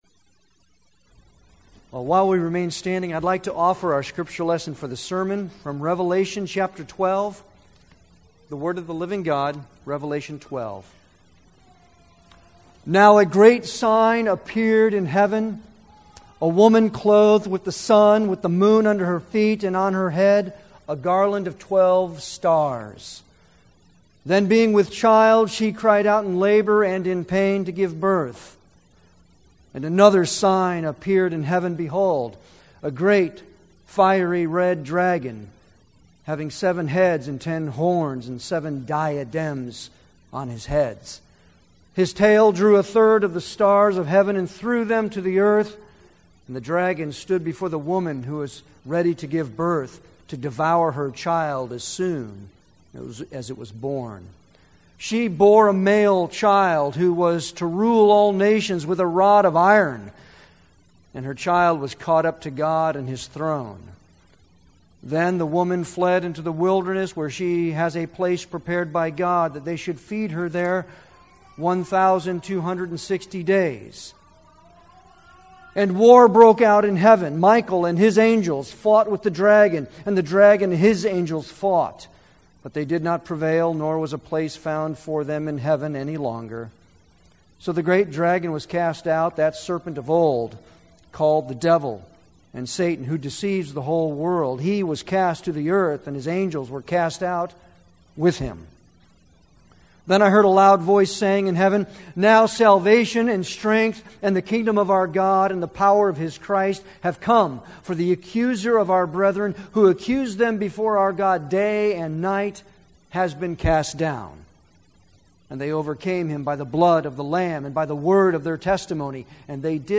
Passage: Revelation 12:1-17 Service Type: Sunday worship